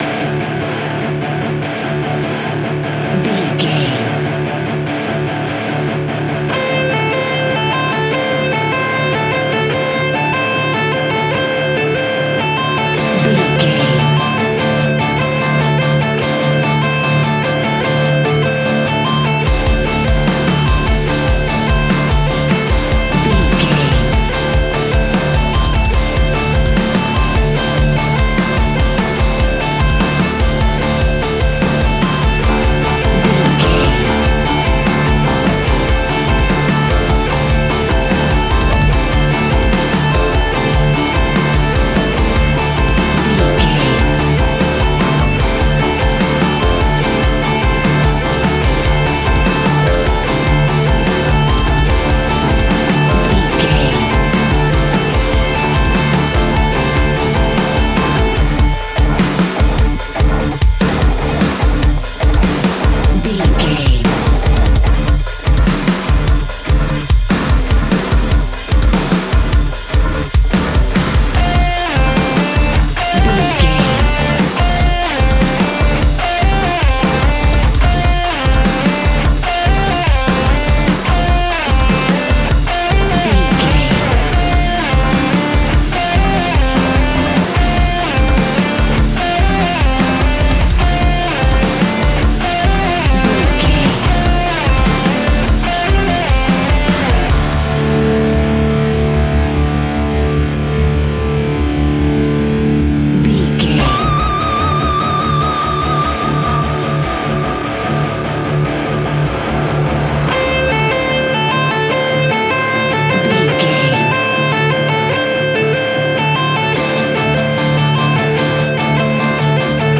Ionian/Major
D♭
hard rock
instrumentals